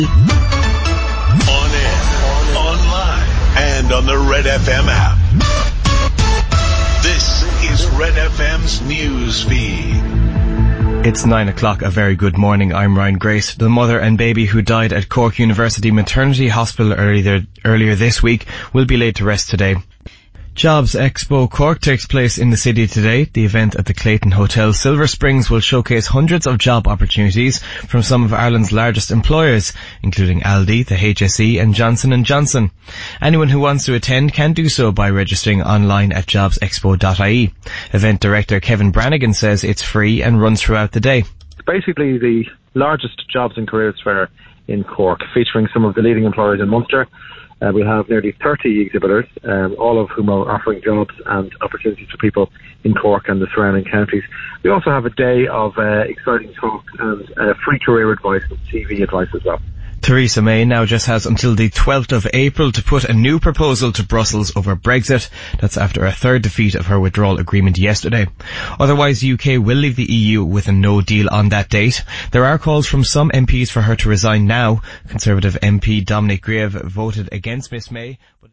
Cork's Red FM feature Jobs Expo Cork in their news bulletins on Saturday.